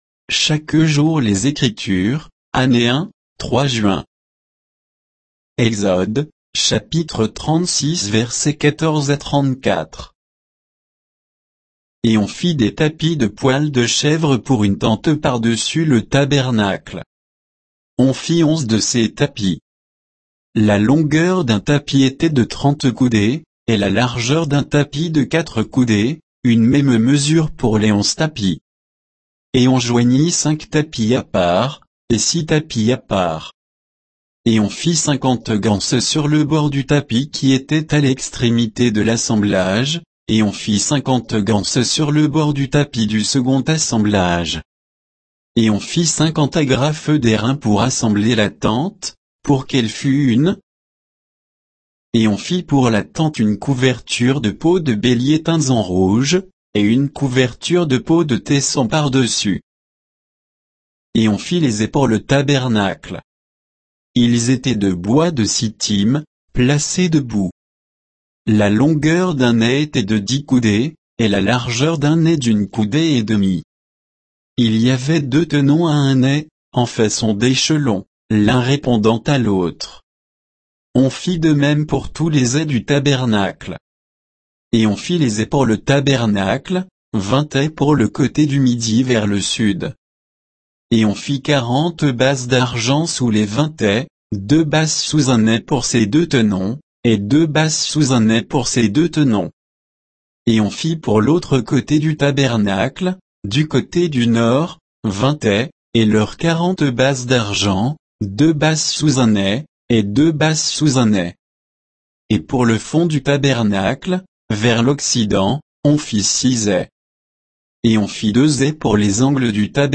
Méditation quoditienne de Chaque jour les Écritures sur Exode 36, 14 à 34